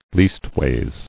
[least·ways]